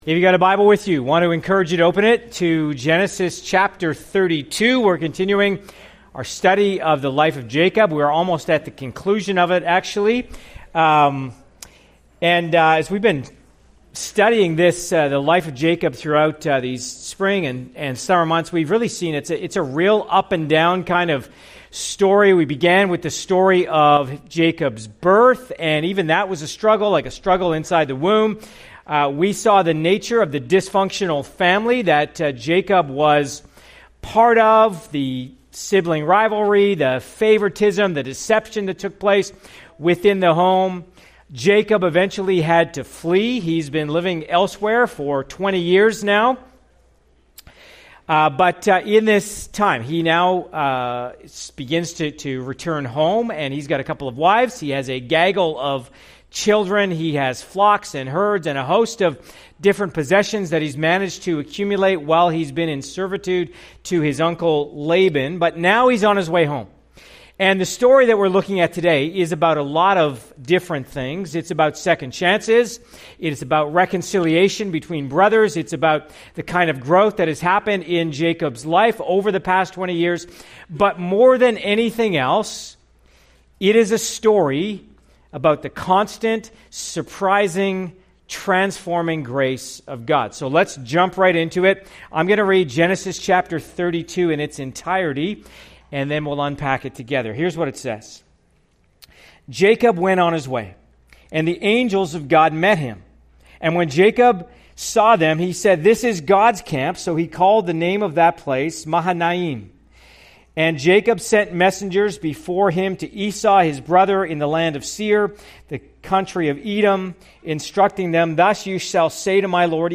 Part of our series, “ Straight Lines with Crooked Sticks ,” following the life of Jacob in the book of Genesis. CLICK HERE for other sermons from this series.